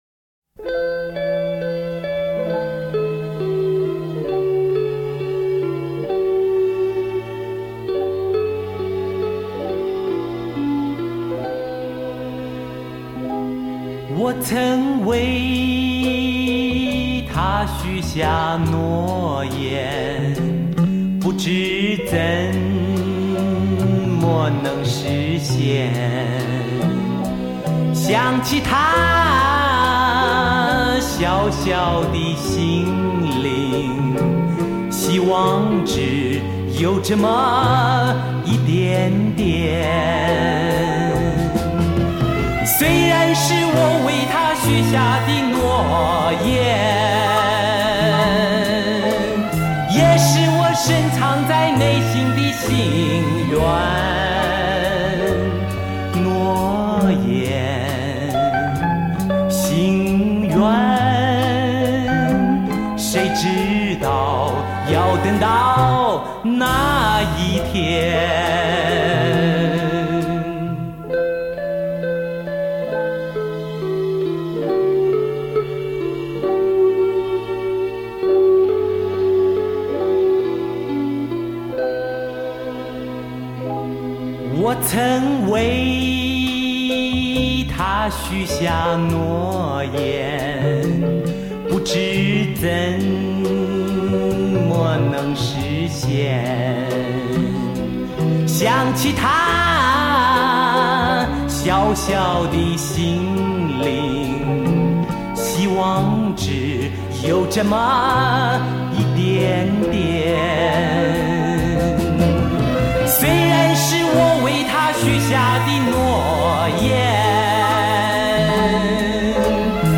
全部24Bit数码录音